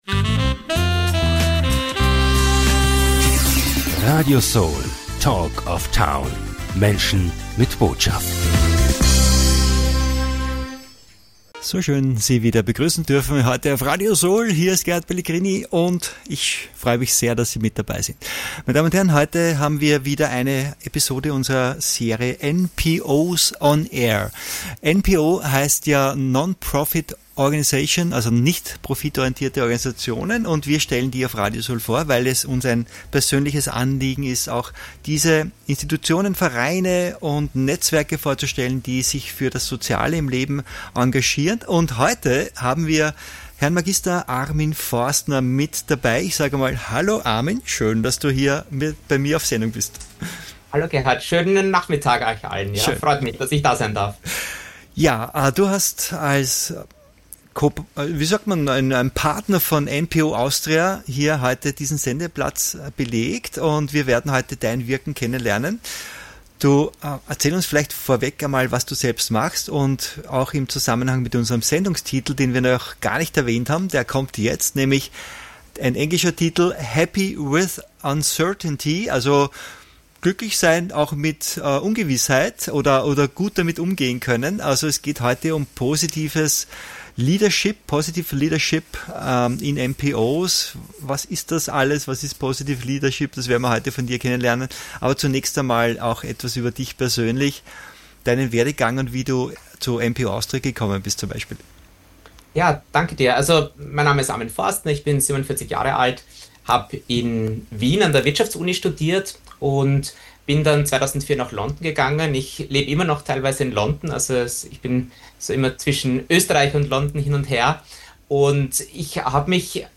Warum diese Aussage kein provokanter Slogan, sondern eine dringende Notwendigkeit ist, erfahren Sie im inspirierenden Interview